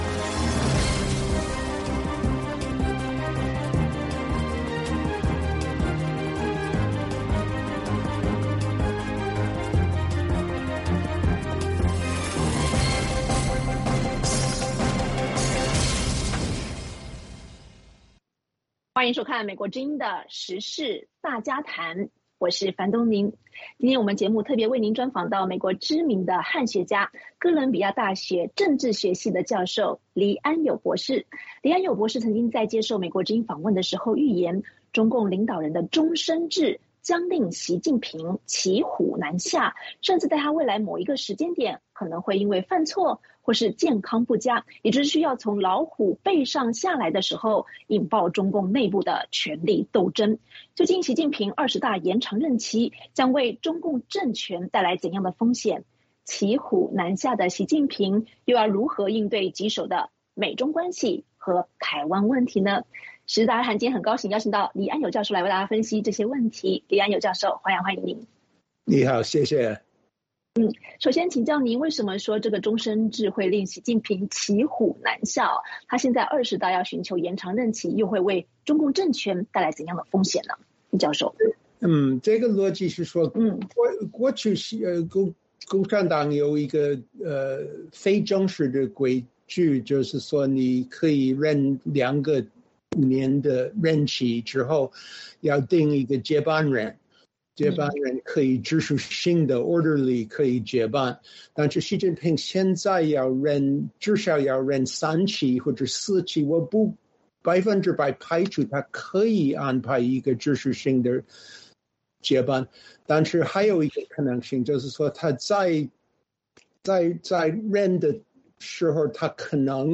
本期时事大家谈专访美国知名汉学家、哥伦比亚大学政治学系教授黎安友。